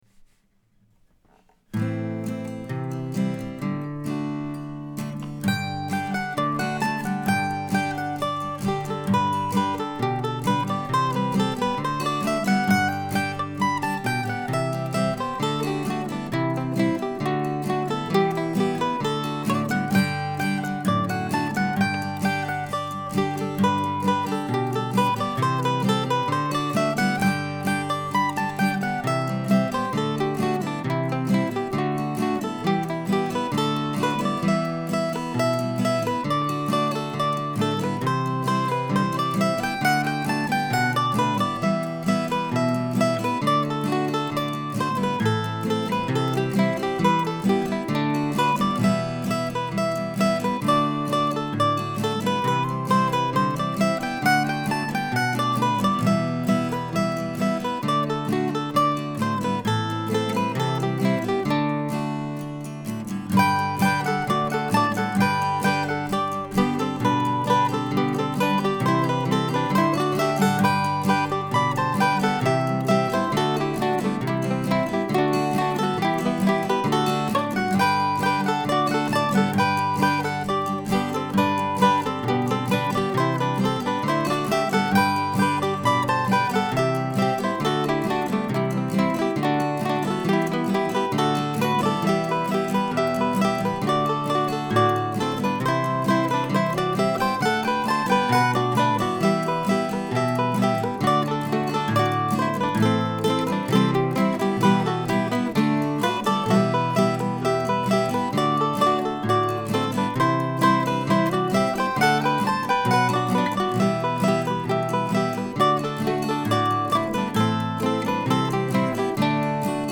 I was lucky and finished the last track just before one of our neighbors got out his loud leaf blower.